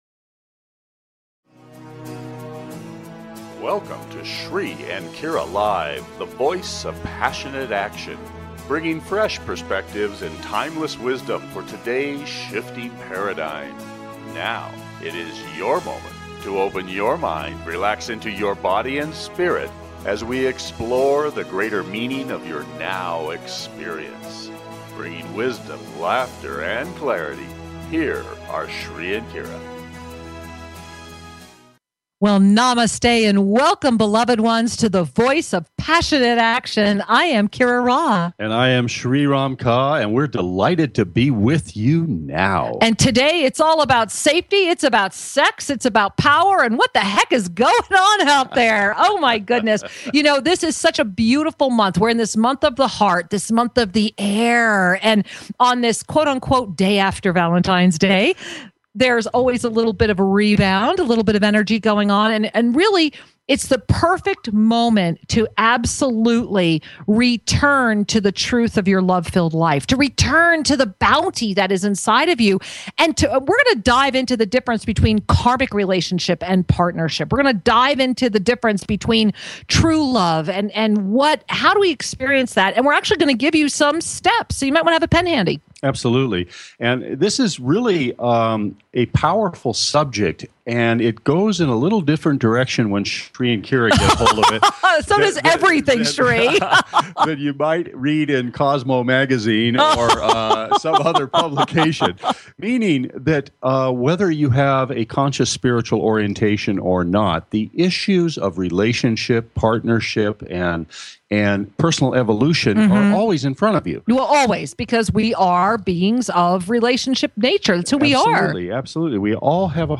Talk Show Episode
We welcome your calls – join the conversation about the pain and the joy of relationships.